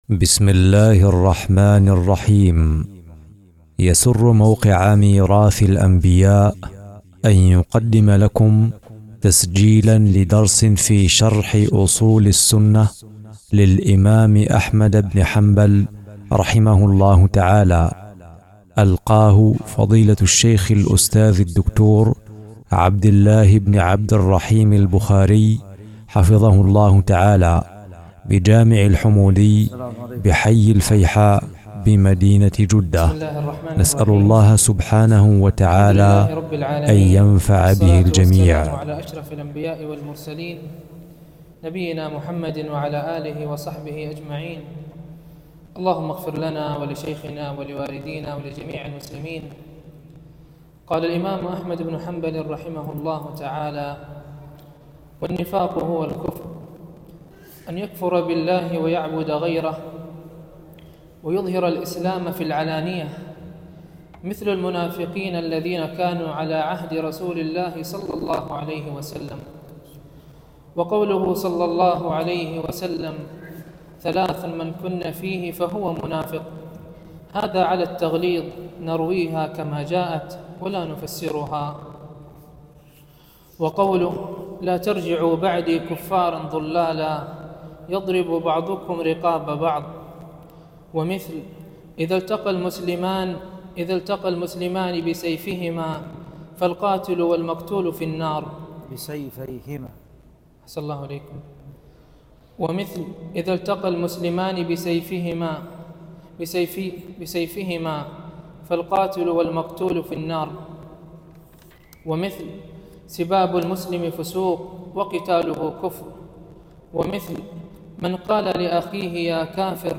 بجامع الحمودي بحي الفيحاء بجدة
الدرس